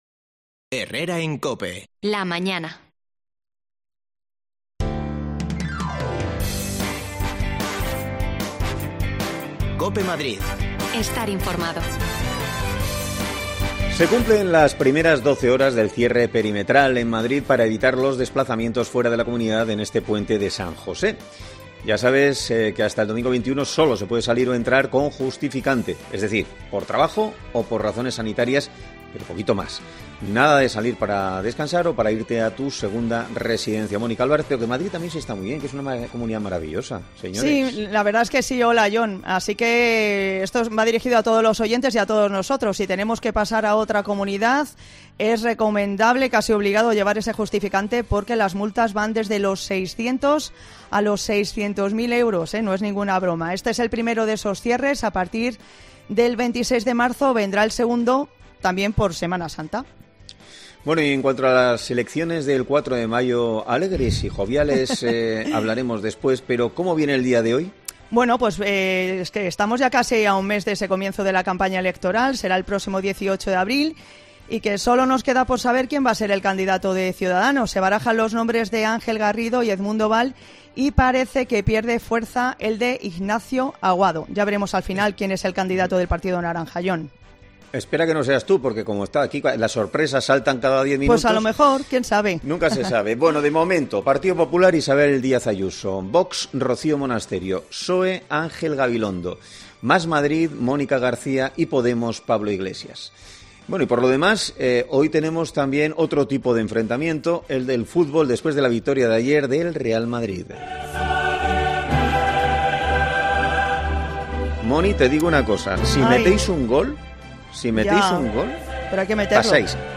Herrera en COPE Madrid
Las desconexiones locales de Madrid son espacios de 10 minutos de duración que se emiten en COPE , de lunes a viernes.